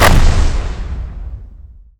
polygon_explosion_nuke4.wav